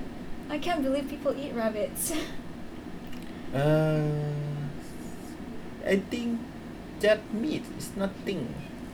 S1 = Brunei female S2 = Laos male
Intended Word: just Heard as: their Discussion: The [s] is omitted.